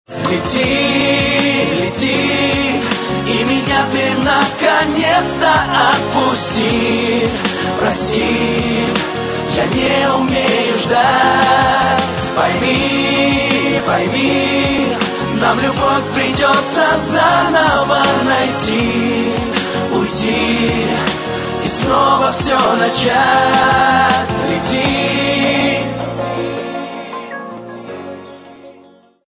Попса [41]
Pop